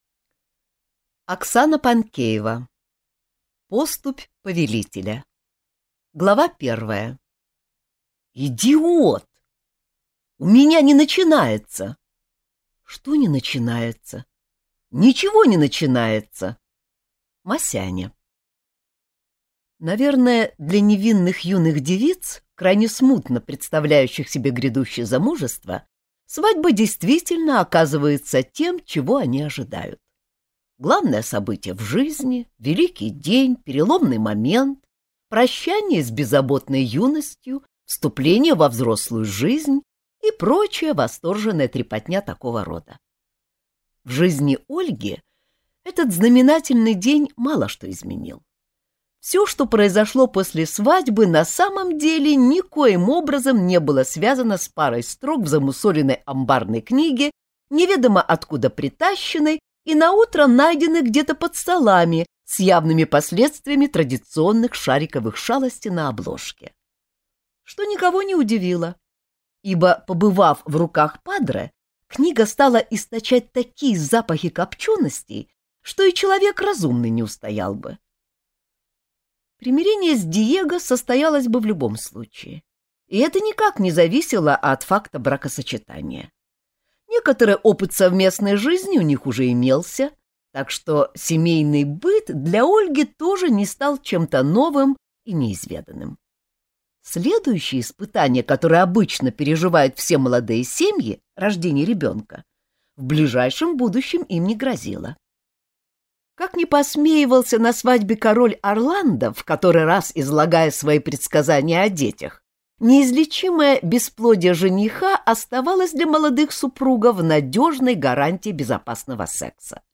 Аудиокнига Поступь Повелителя | Библиотека аудиокниг